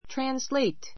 trǽnsleit